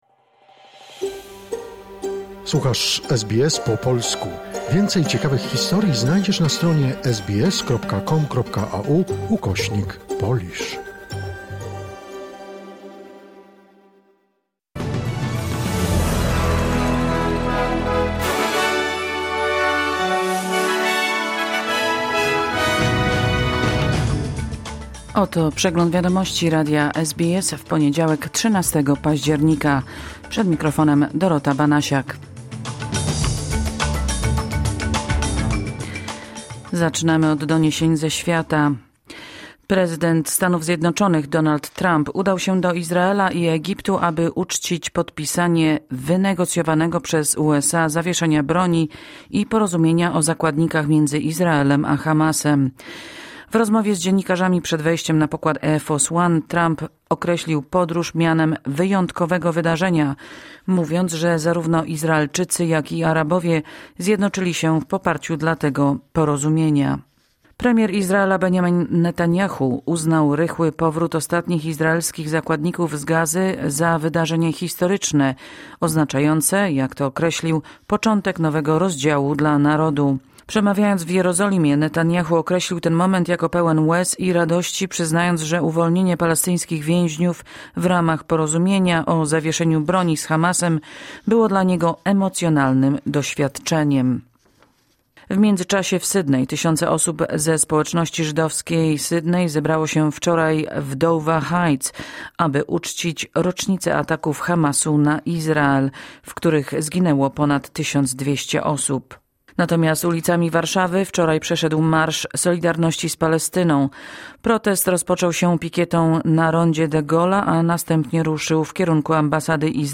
Wiadomości 13 października SBS News Flash